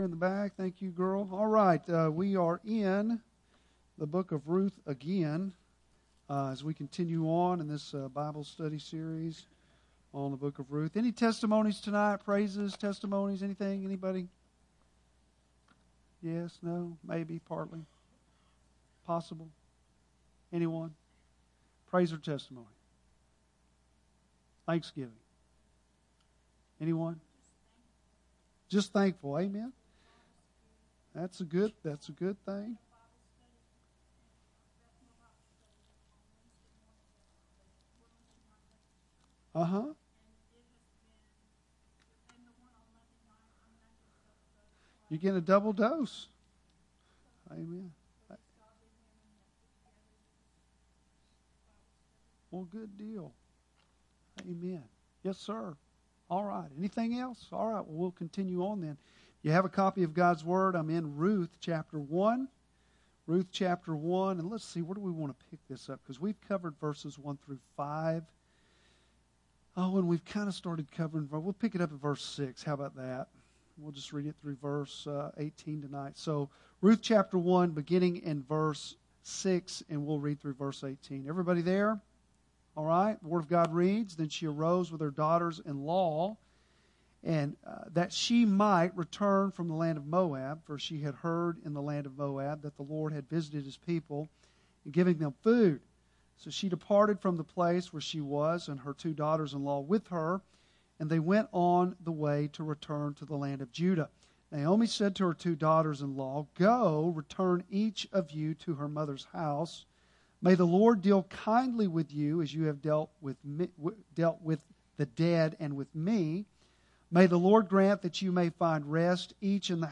Bible Text: Ruth 1:6-20 | Preacher